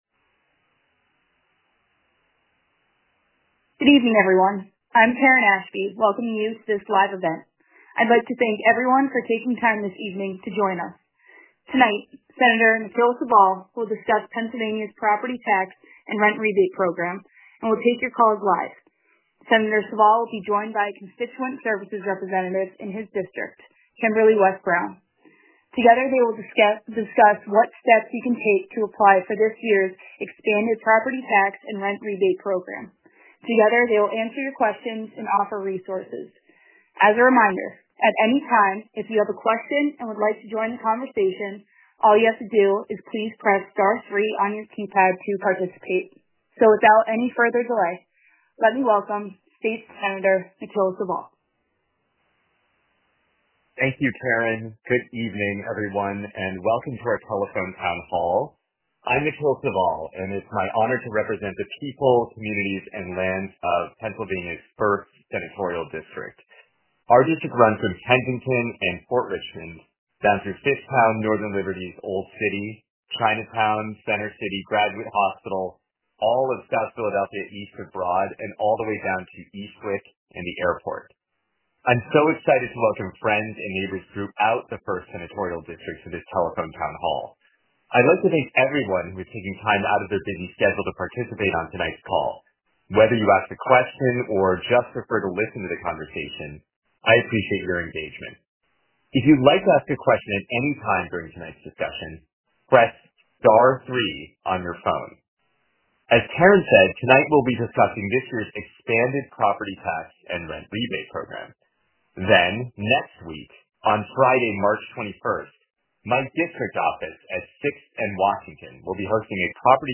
Telephone Town Hall